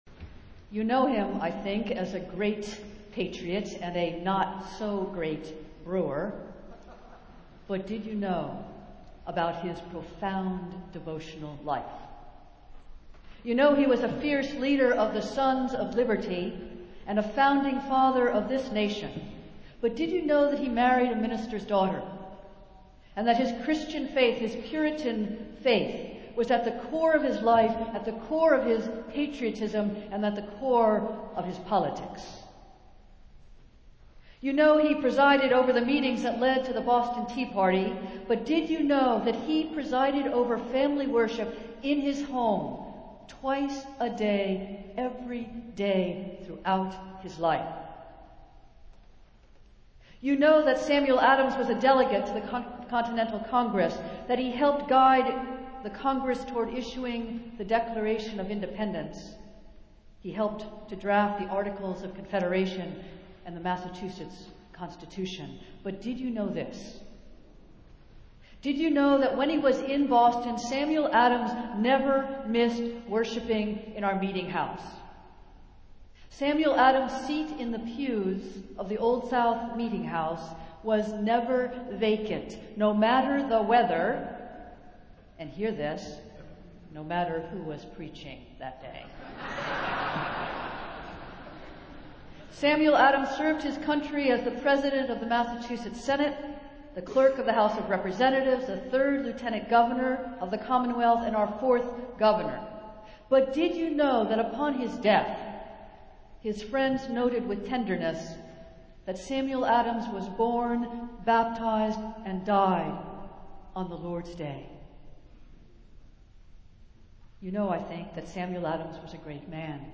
Festival Worship - Hymn Festival Sunday